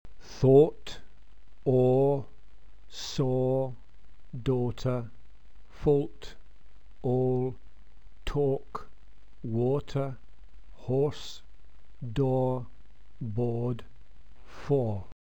English tense vowels